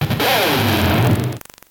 Cri de Crocorible dans Pokémon Noir et Blanc.